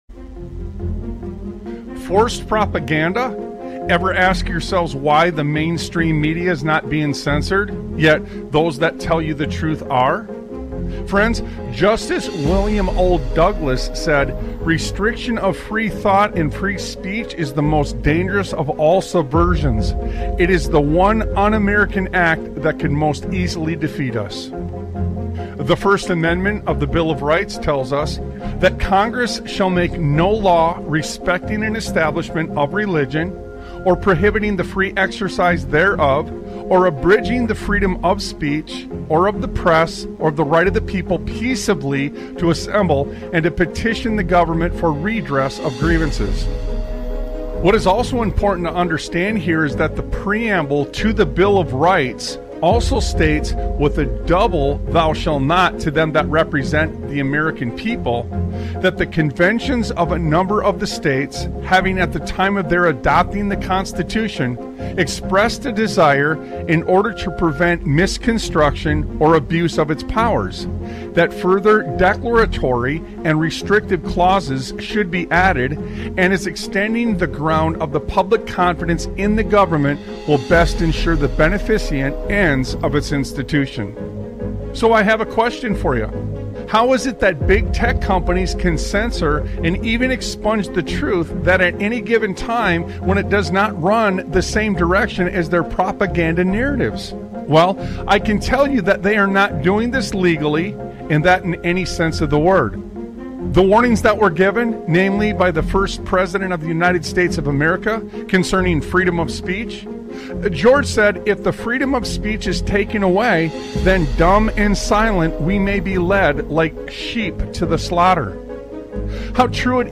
Talk Show Episode, Audio Podcast, Sons of Liberty Radio and And The People Do Nothing! on , show guests , about And The People Do Nothing, categorized as Education,History,Military,News,Politics & Government,Religion,Christianity,Society and Culture,Theory & Conspiracy